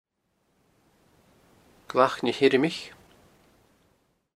the local Applecross Gaelic dialect pronounces the last element as Ioramaich